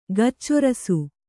♪ gaccorasu